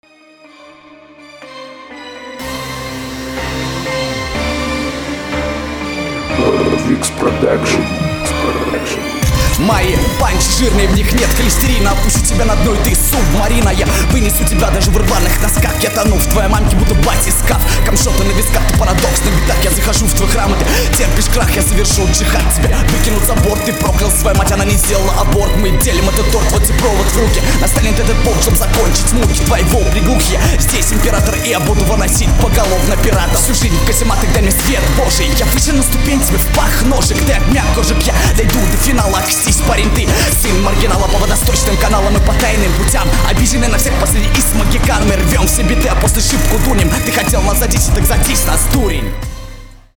Звучит не примечательно.
Читка обычная, в тексте ничего особо запоминающегося.